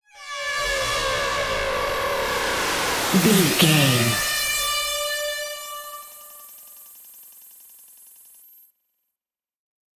Atonal
Fast
scary
ominous
dark
haunting
eerie
industrial
synthesiser
keyboards
ambience
pads
eletronic